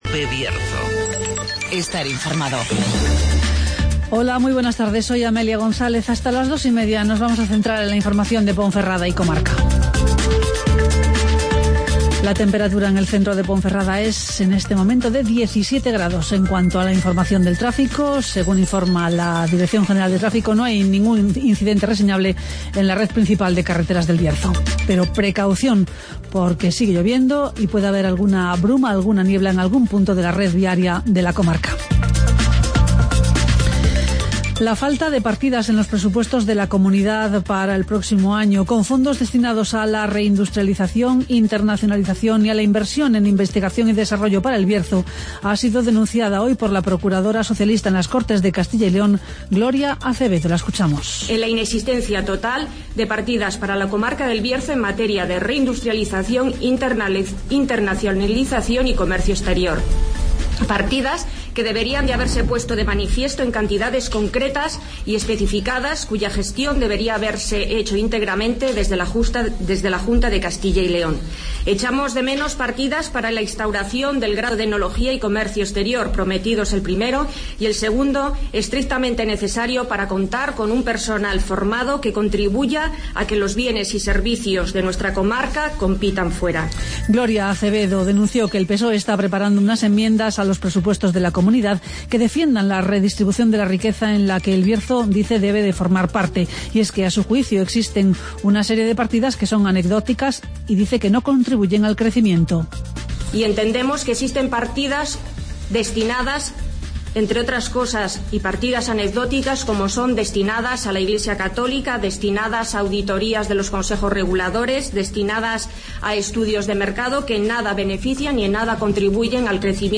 Informativo Mediodía COPE Bierzo 19-10-15